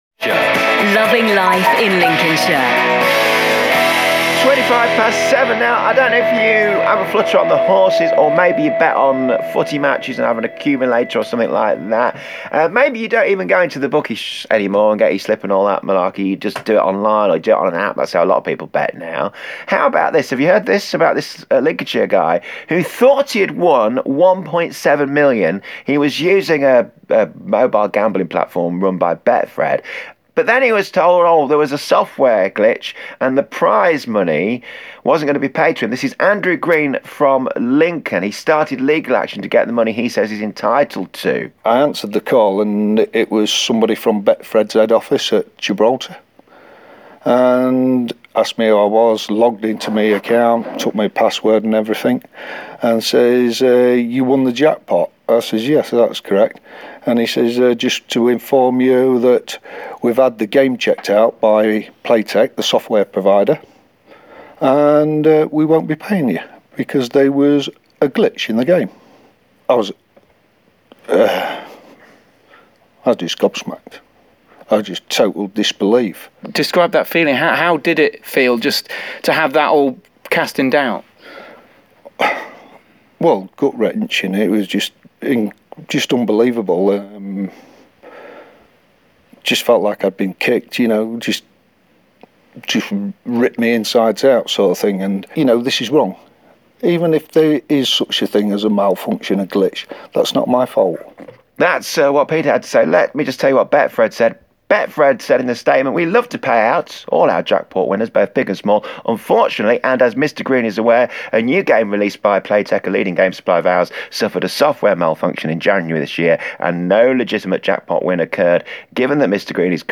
interview on BBC Radio Lincolnshire’s Breakfast Show yesterday morning.